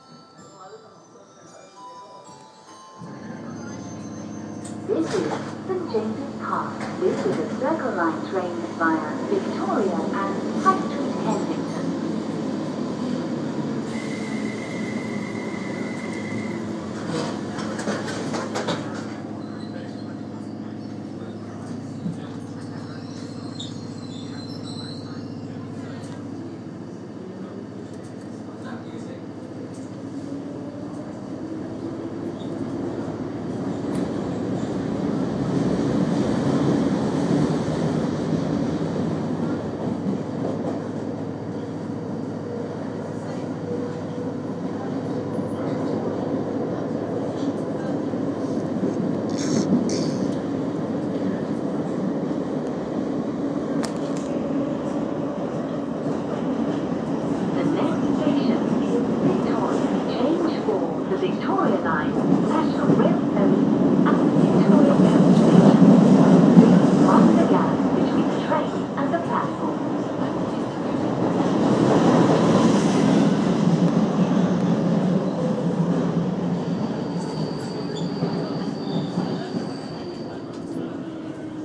Bells on Circle Line